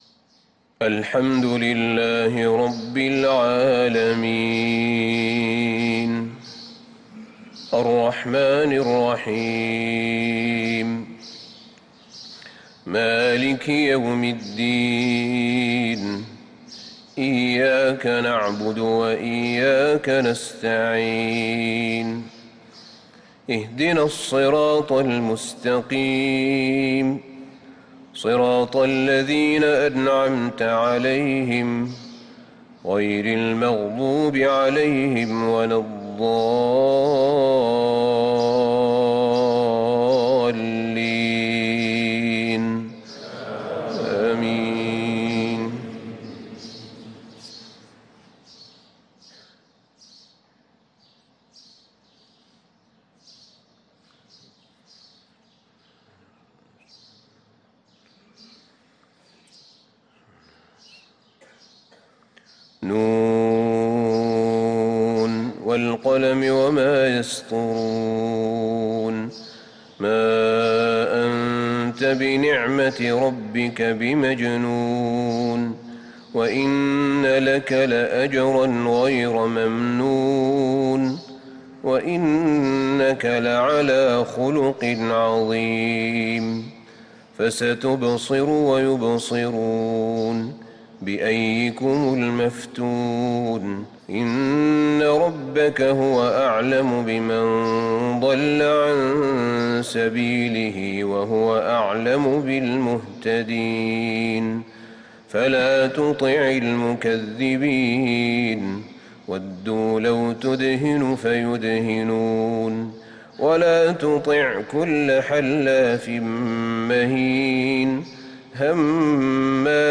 صلاة الفجر للقارئ أحمد بن طالب حميد 3 رجب 1445 هـ